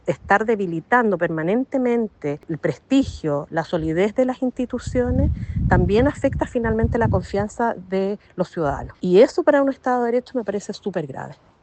Una visión similar tiene la exministra de Justicia, Javiera Blanco, quien calificó como “preocupante” que la discusión se centre en la militancia política de la actual directora. A su juicio, el foco debería estar en resguardar el carácter técnico y la institucionalidad del organismo.